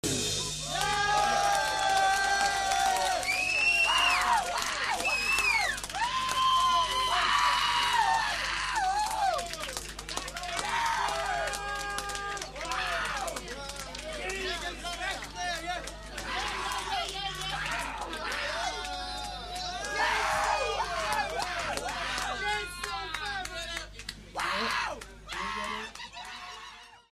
Flamman August 18, 1979
This was possible due to the Tandberg built in mixer of two mics with stereo line input.
audience.mp3